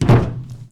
kathunk.wav